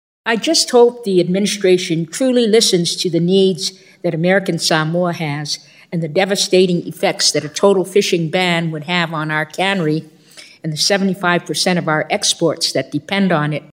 It was the latest attempt by Congresswoman Amata to get the administration to pay attention to the needs of American Samoa’s commercial fishing industry—this time in person, with agency officials at the witness table…